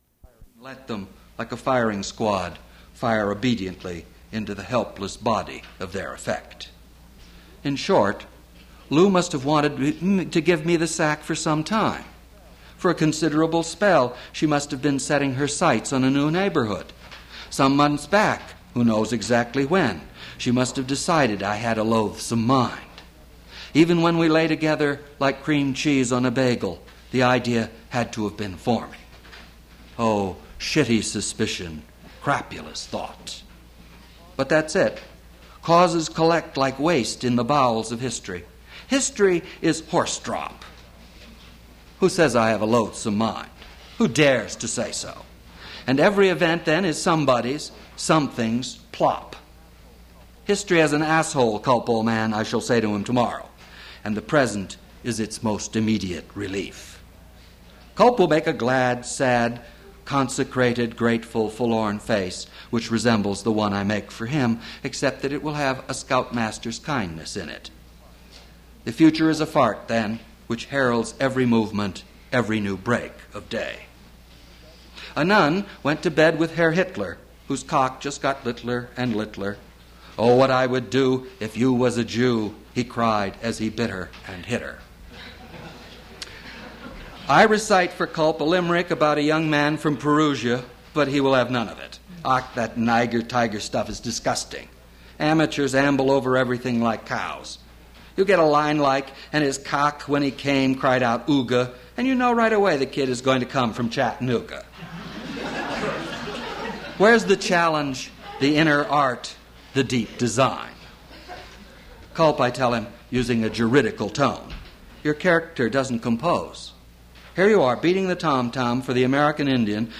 Novel reading featuring William Gass
• William Gass reading "Culp" from his novel "The Tunnel" at Duff's Restaurant.
• mp3 edited access file was created from unedited access file which was sourced from preservation WAV file that was generated from original audio cassette.